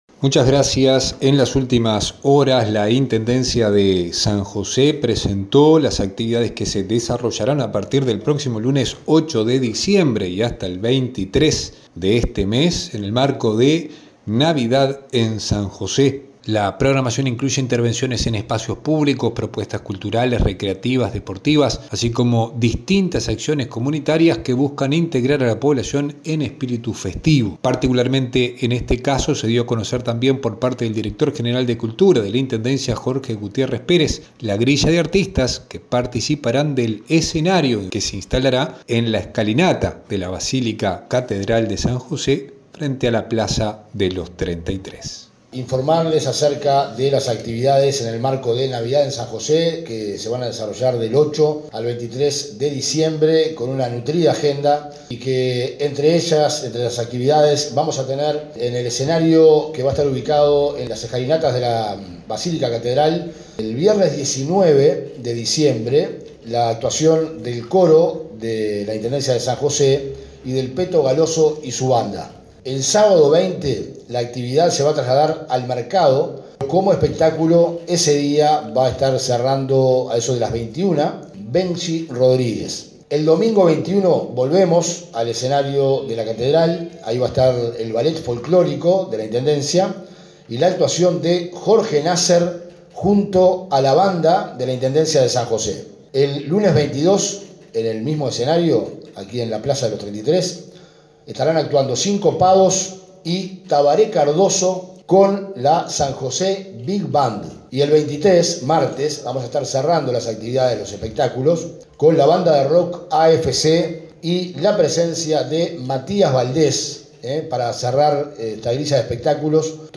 El lanzamiento se realizó en el Espacio Cultural San José, con la participación de autoridades de Cultura, Juventud, Descentralización y Desarrollo, quienes destacaron el carácter abierto y gratuito de todas las actividades y el objetivo de consolidar una celebración participativa y descentralizada en cada localidad.
Para el caso, el Director General de Cultura, dio a conocer la grilla de artistas que participarán del escenario en la Plaza de los «33».